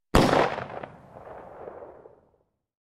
Звук выстрела из Макарова с эхом